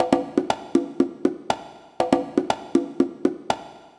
120_conga_2.wav